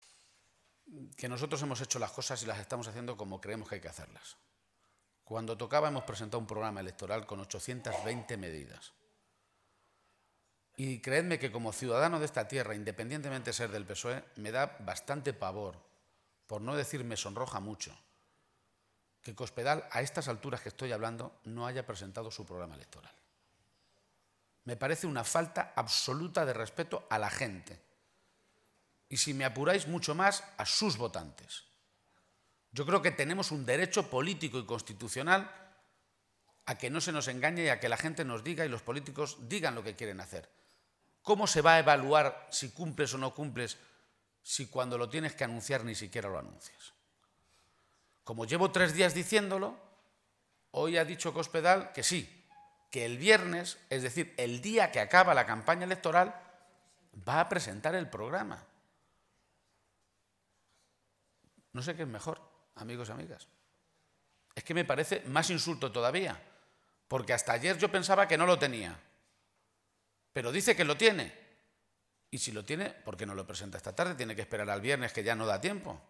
García-Page realizó este anuncio en una comida con militantes y simpatizantes en la localidad toledana de Torrijos, después de visitar esta mañana la zona afectada y mantener un encuentro con la alcaldesa de El Provencio.